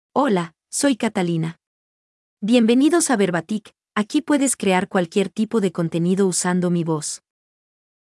Catalina — Female Spanish (Chile) AI Voice | TTS, Voice Cloning & Video | Verbatik AI
Catalina is a female AI voice for Spanish (Chile).
Voice sample
Female
Catalina delivers clear pronunciation with authentic Chile Spanish intonation, making your content sound professionally produced.